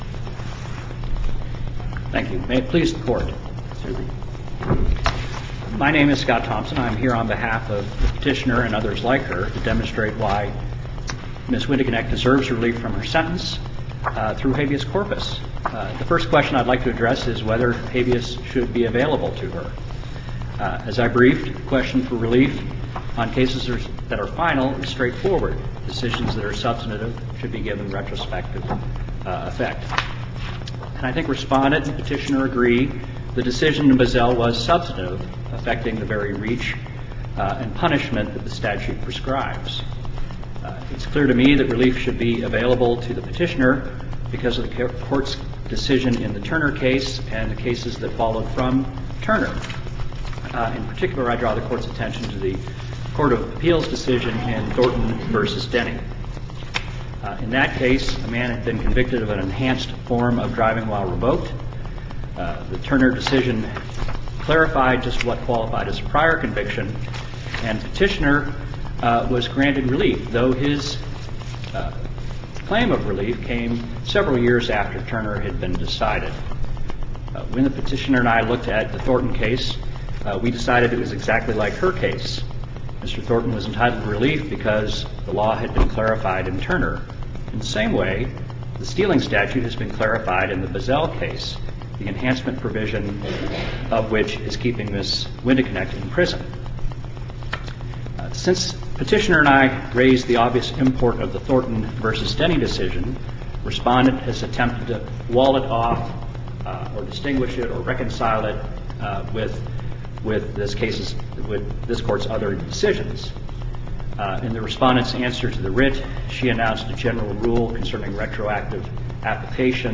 MP3 audio file of arguments in SC96159